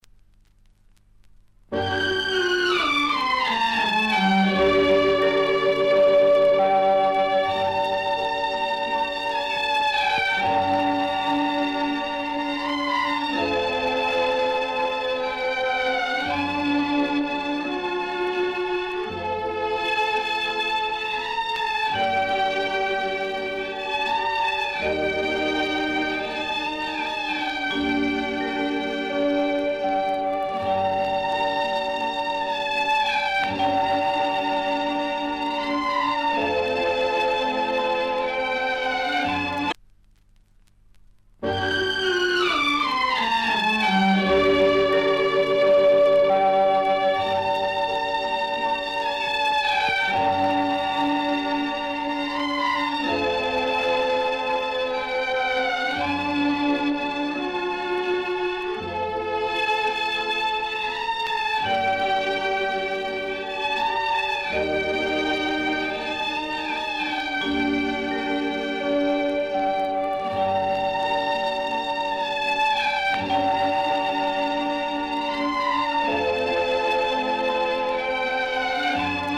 Artist: Instrumental